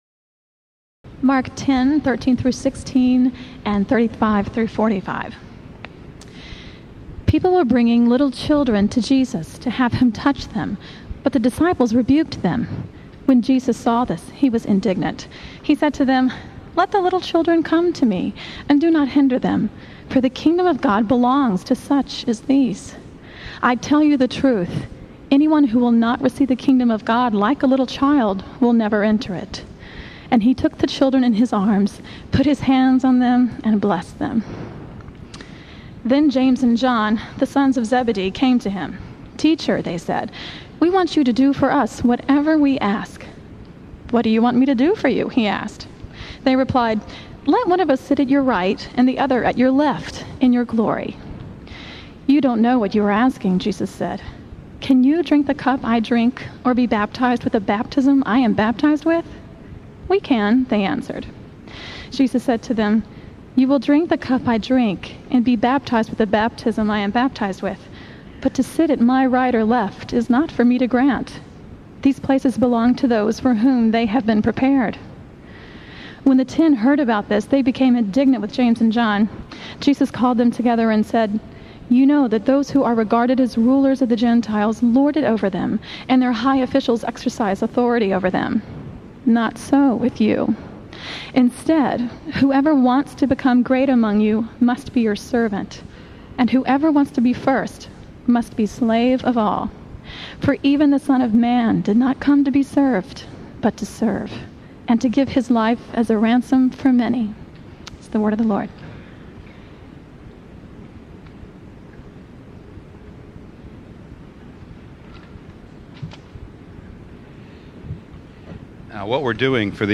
The following sermons are in MP3 format.
In this sermon Tim Keller explains the objective and subjective meanings of the cross. Objectively,Jesus paid the debt we owe that separates us from God.